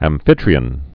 (ăm-fĭtrē-ən)